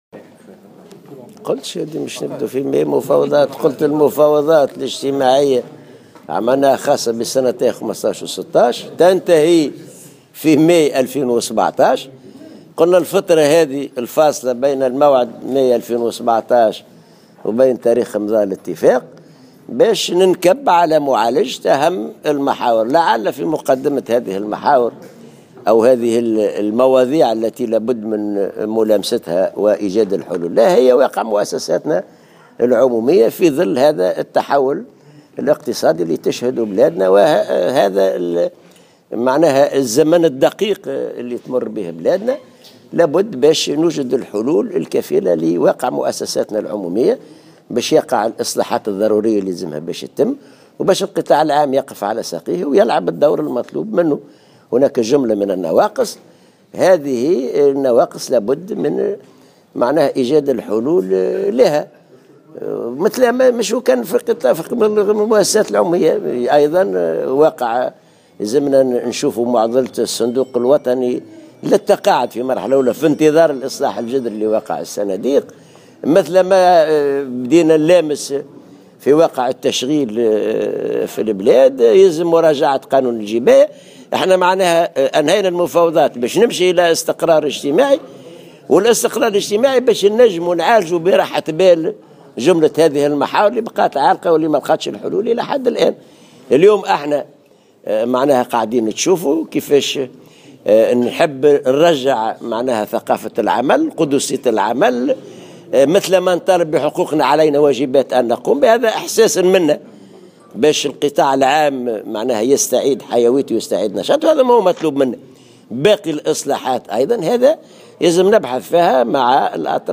وأضاف في تصريحات صحفية على هامش اجتماع نقابي حول قطاع النقل انتظم بالحمامات، ان من أهم المحاور الأخرى التي سيتم معالجتها بانتهاء المفاوضات الاجتماعية حول الزيادة بالأجور، تلك المتعلقة أيضا بالصندوق الوطني للتقاعد ومراجعة قانون الجباية.